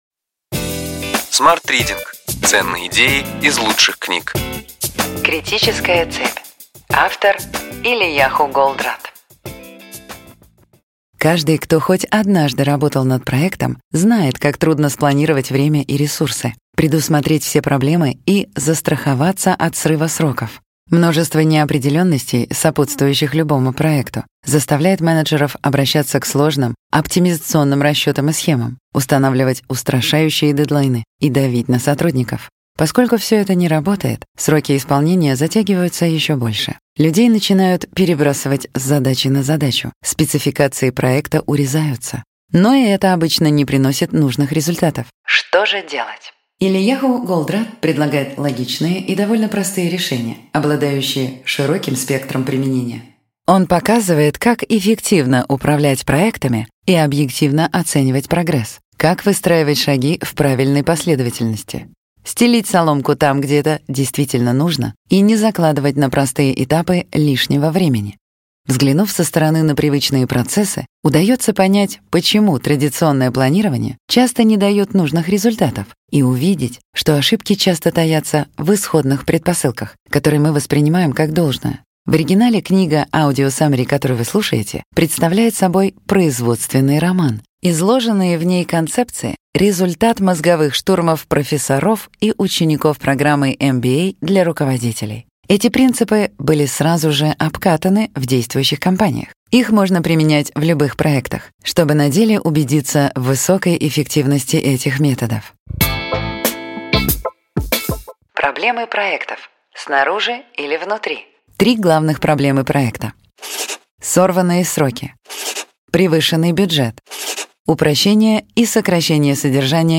Аудиокнига Ключевые идеи книги: Критическая цепь. Элияху Голдратт | Библиотека аудиокниг